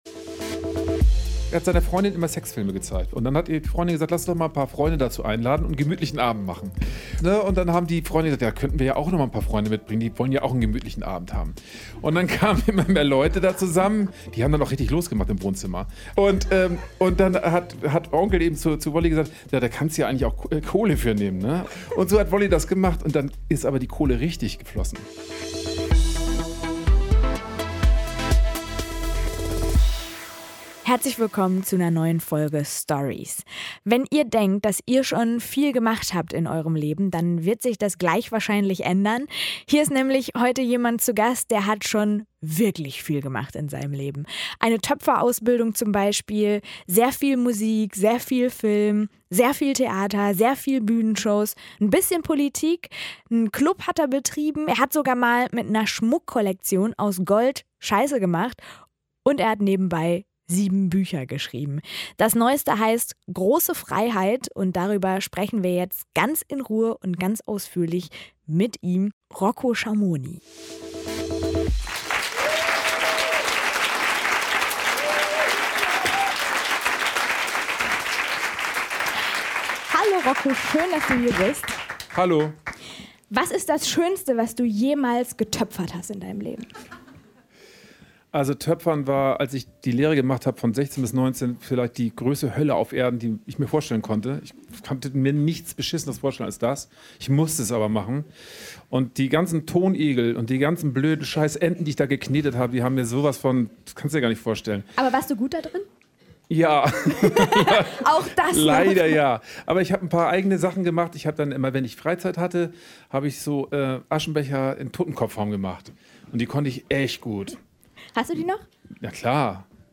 Hier im 'Stories.'-Podcast gibt es jeden Mittwoch „Die Lesung“ inklusive Autoren-Talk und in einem zweiten Teil den "Nerdtalk“.